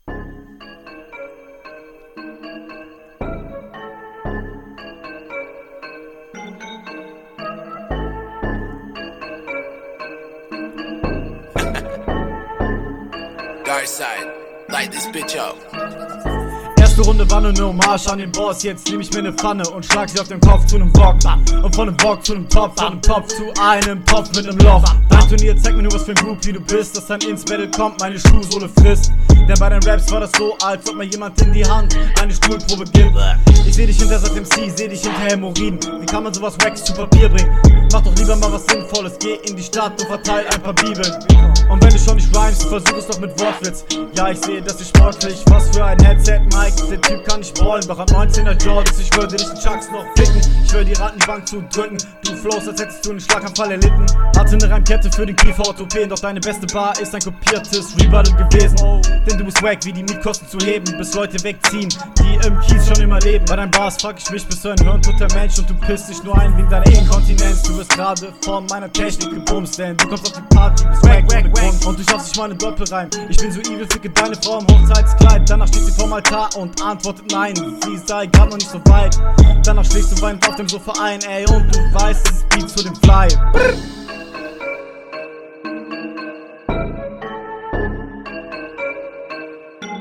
topf/loch-lines fand ich irgendwie komisch. mische hier auch nich so clean wie bei der rr1.
Flow: Du flowst ganz cool auf dem Beat. Das klingt ganz ordentlich.